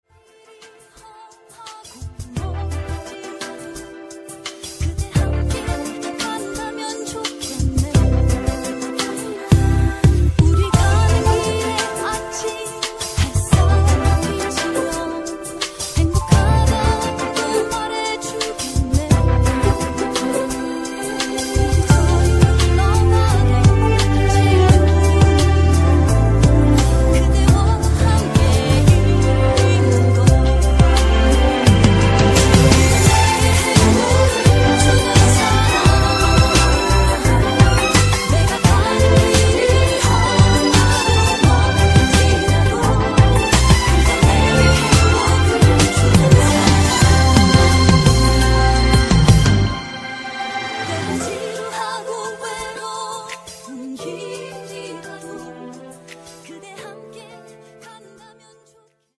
음정 원키 2:50
장르 가요 구분 Voice MR
보이스 MR은 가이드 보컬이 포함되어 있어 유용합니다.